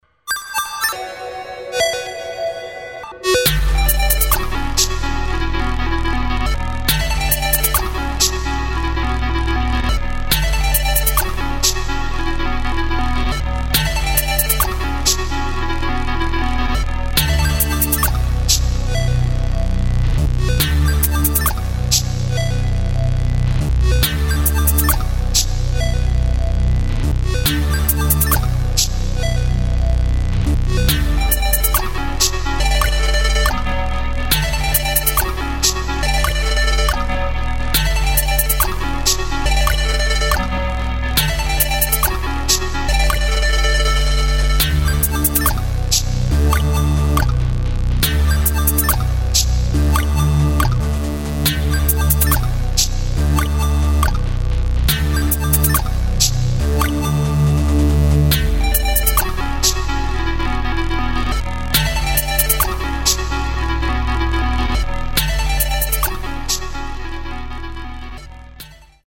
[ DUBSTEP | BASS | EXPERIMENTAL ]